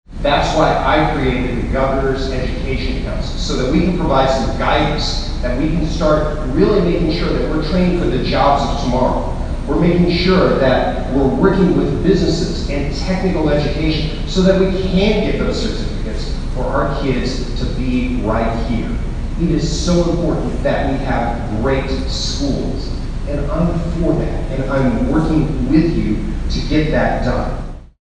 Hundreds of well-wishers, supporters and citizens watched; wearing clothing with slogans, pins and stickers of their favorite candidate as four of the men up for the GOP nomination for Kansas Governor spread their message at the Salina Innovation Center, in downtown.
Governor Colyer talked about his plan for education among other things.